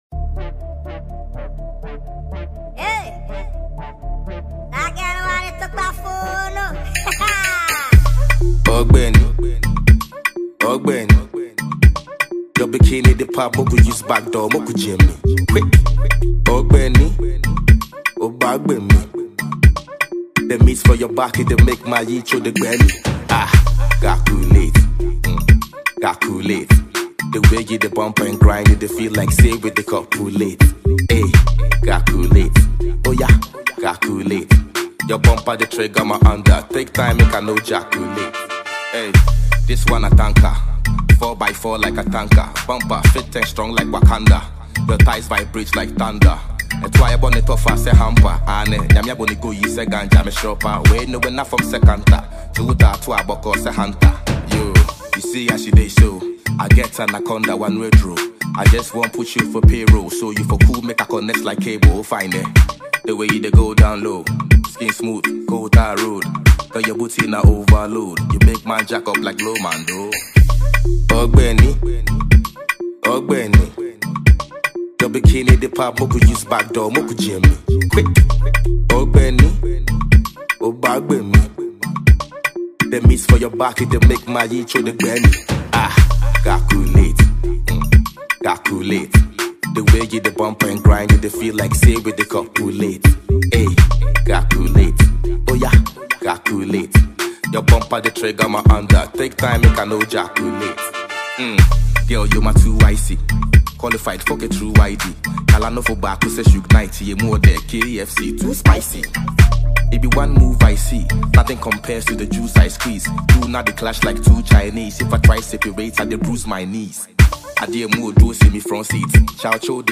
Ghana MusicMusic
Amazing  Ghanaian prolific rapper